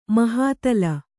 ♪ mahātala